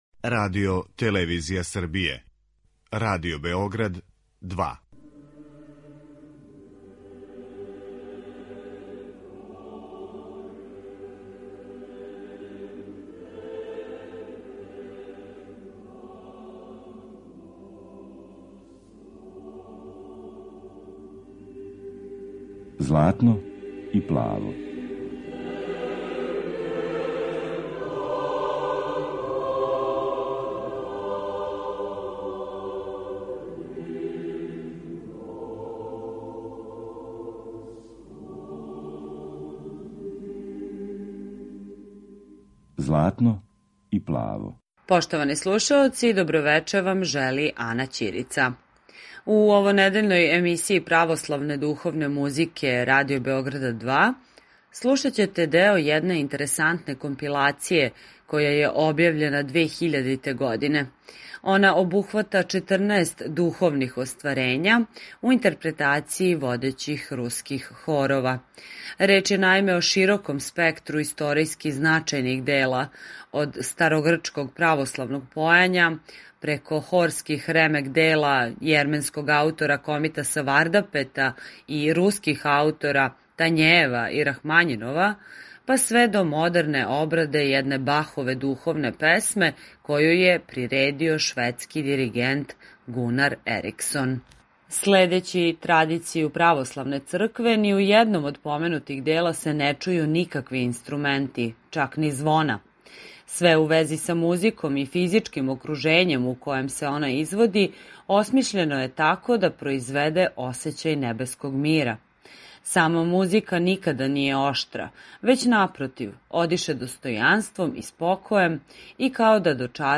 Извођење водећих руских хорова